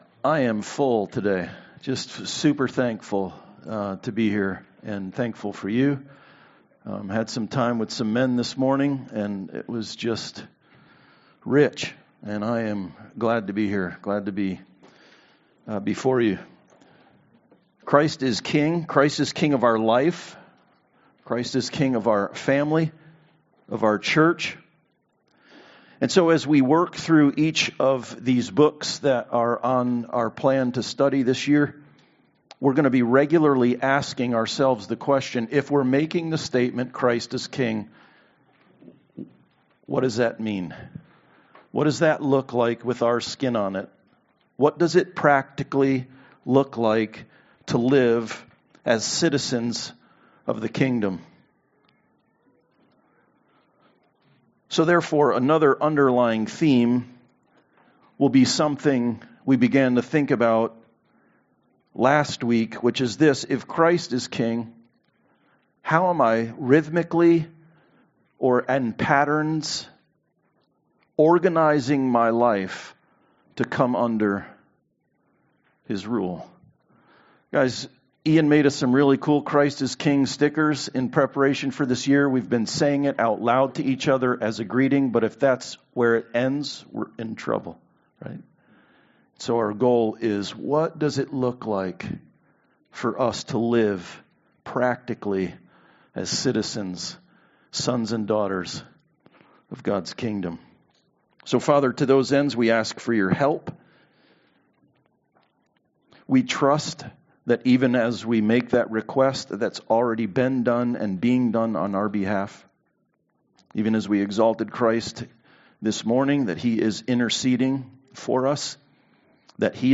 Intro to Jonah Service Type: Sunday Service Jonah is commissioned by God to warn Nineveh of impending judgment.